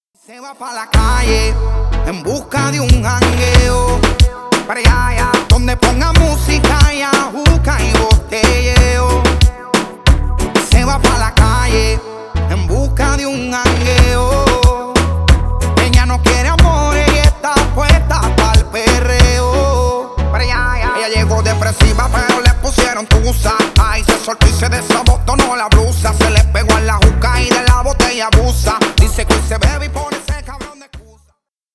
Танцевальные
латинские